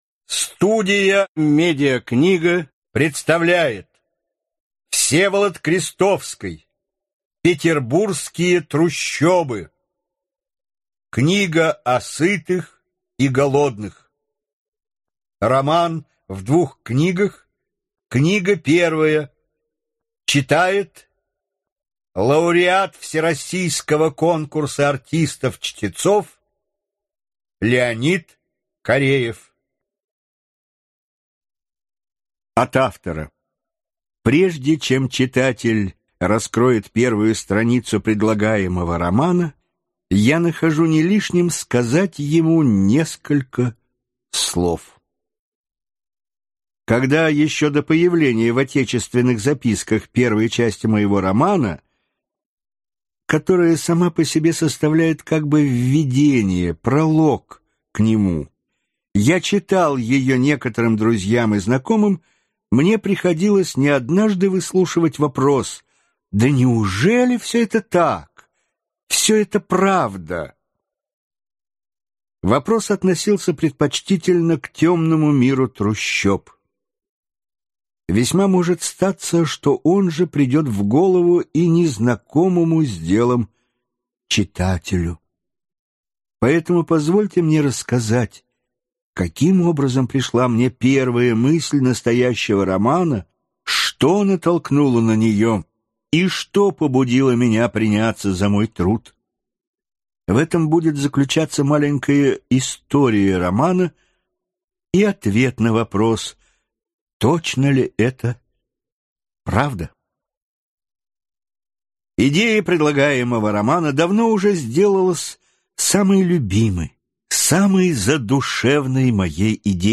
Аудиокнига Петербургские трущобы. Часть 1. Старые годы и старые грехи | Библиотека аудиокниг